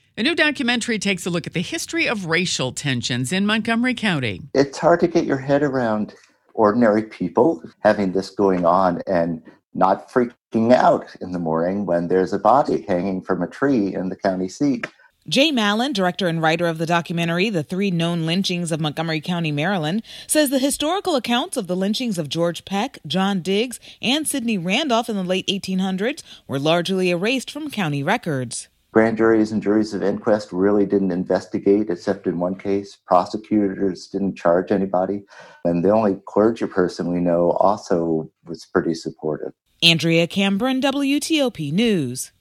Washington-area all-news station WTOP carried two stories on “The Three Lynchings” film as more than 300 people attended an online showing by the Montgomery County Lynching Memorial Project on Nov. 15.